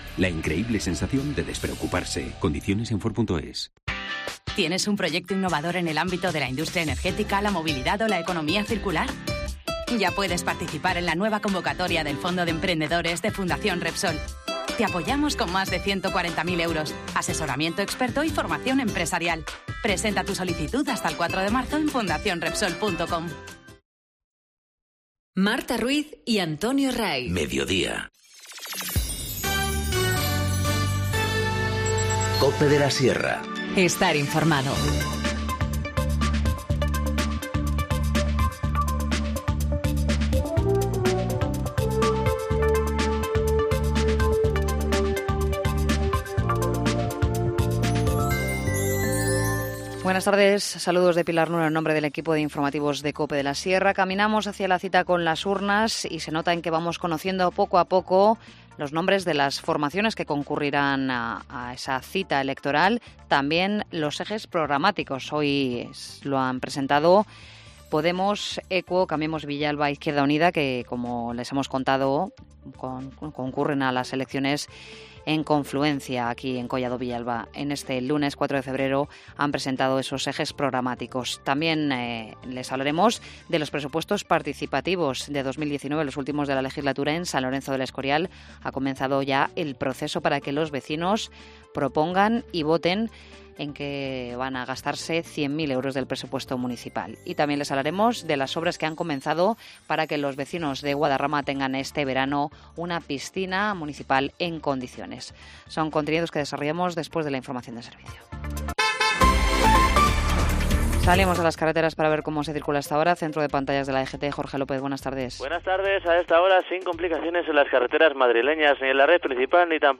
Informativo Mediodía 4 febrero- 14:20h